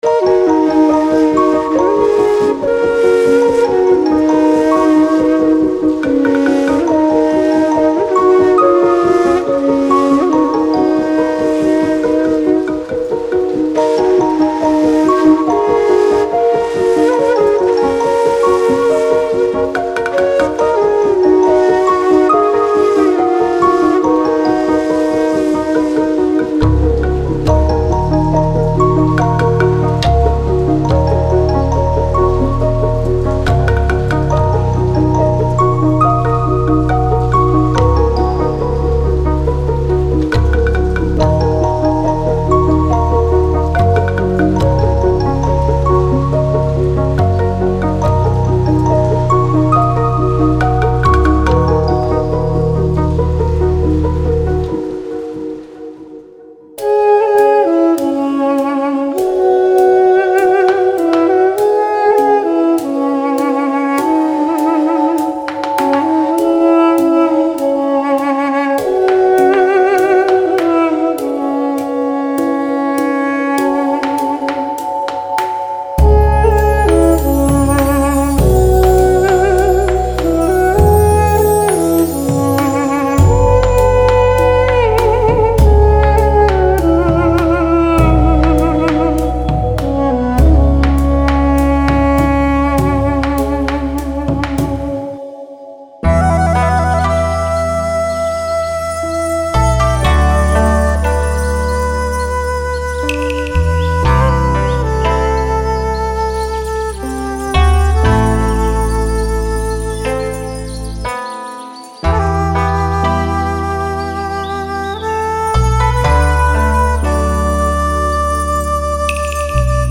Genre:Ambient
ここでは、日本の尺八や中国の笛であるディジ、ペルシャのネイ、アルメニアのドゥドゥクなどの珍しい楽器を発見できます。
弦楽器では、日本の三味線、アフリカのボロン、中国の二胡などがあります。
このパックは、ダウンテンポ、アンビエント、チルアウト、瞑想音楽の制作に最適です。
デモサウンドはコチラ↓
Tempo/Bpm 60-120